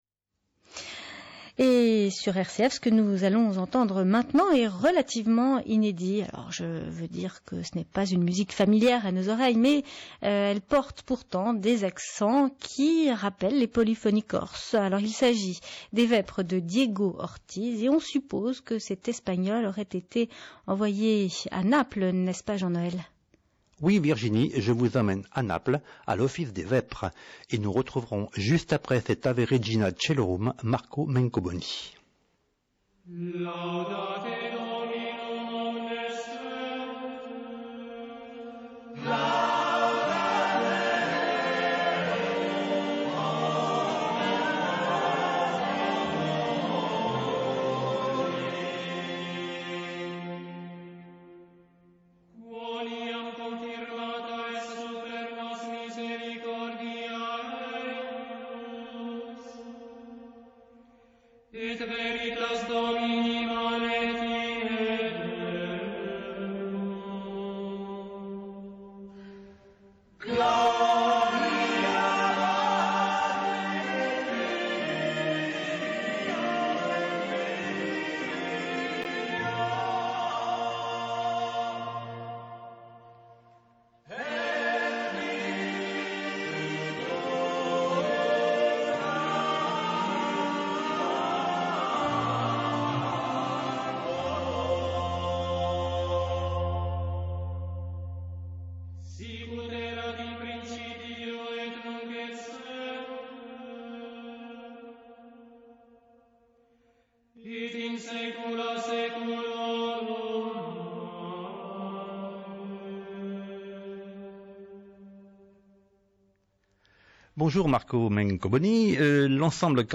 rcf_interview.mp3